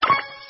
铁匠-放置材料音效.mp3